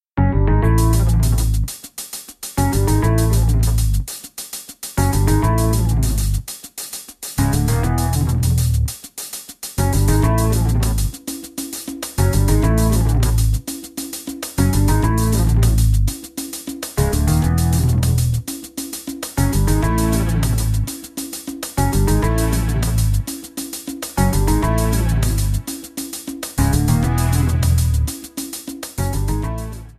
Backing track Karaoke
Pop, Rock, 1990s